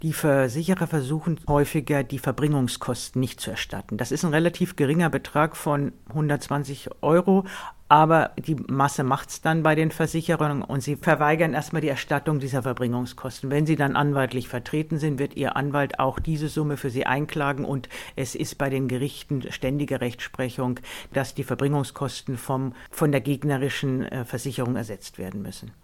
O-Ton: Versicherungen tricksen immer mal bei den Verbringungskosten